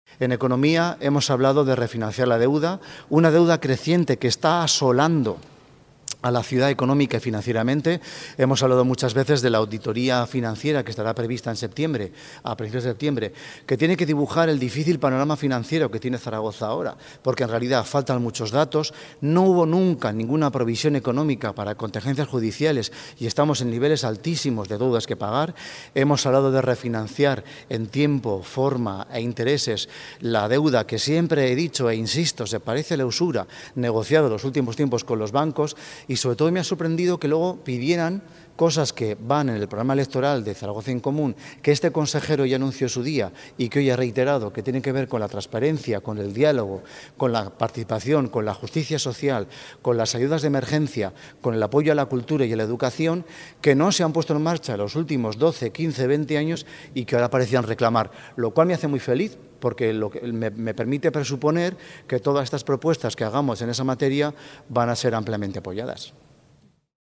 Fernando Rivarés  anuncia el desarrollo de la Oficina de Control Financiero en su primera comparecencia en la Comisión de Pleno, celebrada hoy
Fernando Rivarés, explicando las prioridades en materia económica: